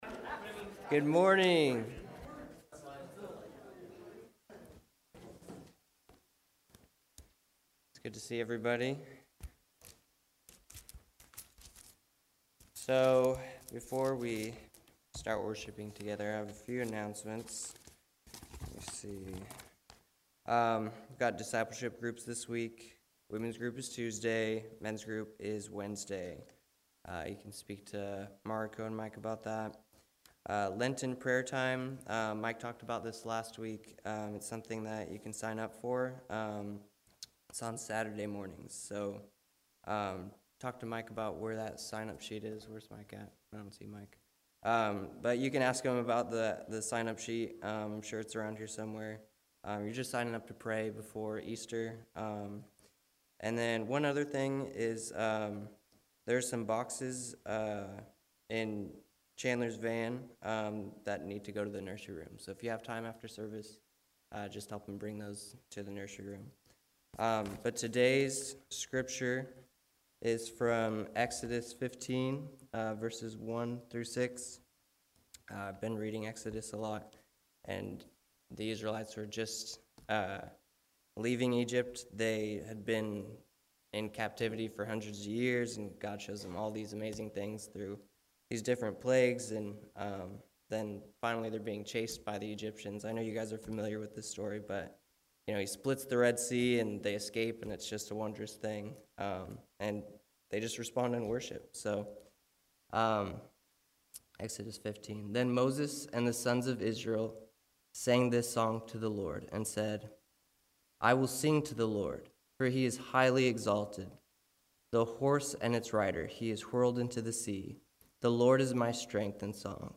February 20 Worship Audio – Full Service
Luke 20:1-44 Service Type: Morning Worship A video of today’s service can be seen on the Vine Street Baptist Church Facebook Page .